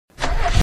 enginestart.mp3